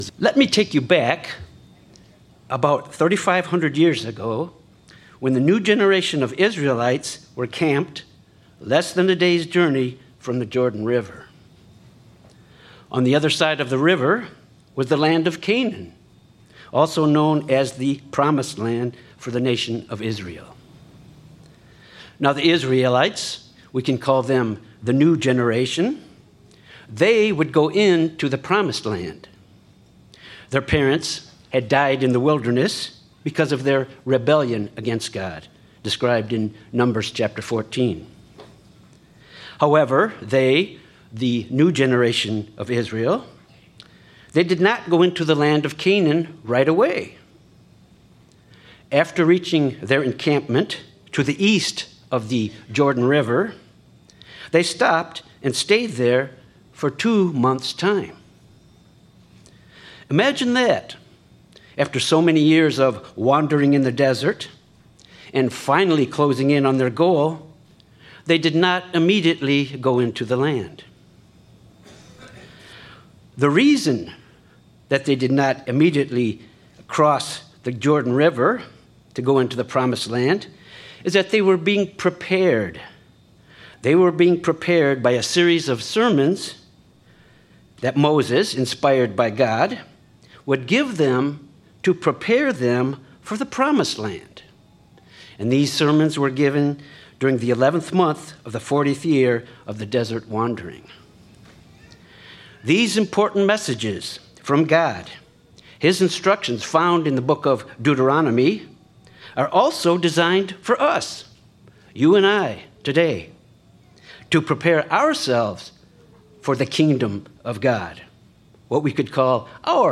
Given in Branson, Missouri
The messages in Deuteronomy help us in our preparation for the Kingdom of God 1st day message Studying the bible?